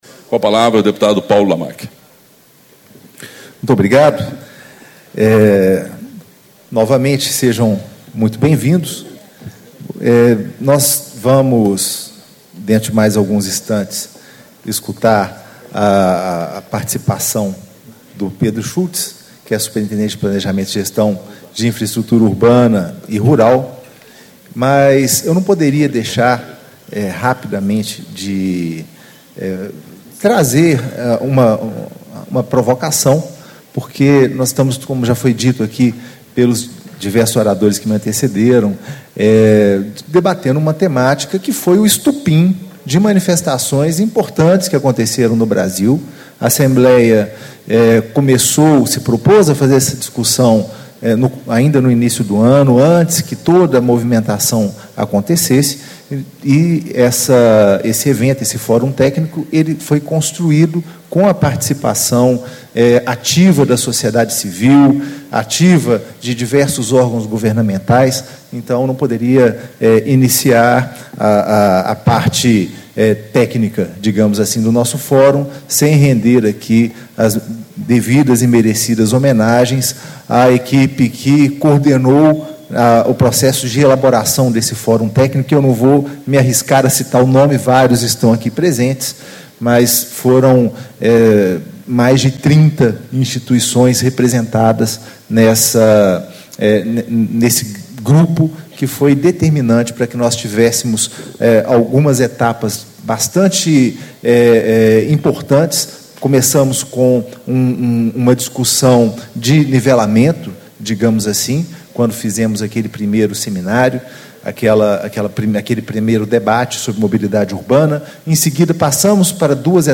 Apresentação Inicial - Deputado Paulo Lamac, PT - Presidente da Comissão de Assuntos Municipais e Regionalização
Encontro Estadual do Fórum Técnico Mobilidade Urbana - Construindo Cidades Inteligentes